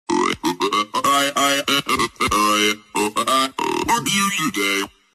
oi oi oe oi a eye eye Meme Sound Effect
oi oi oe oi a eye eye.mp3